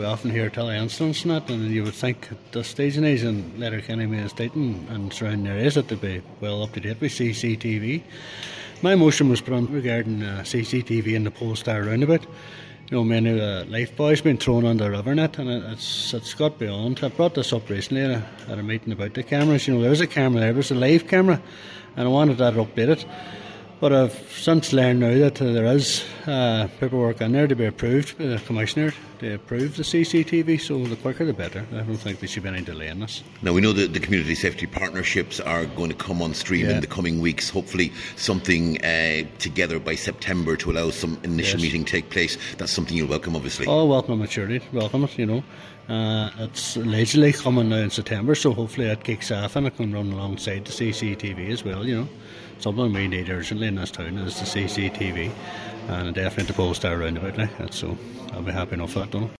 Cllr Devine says the expansion of CCTV coverage in Letterkenny needs to be progressed quickly: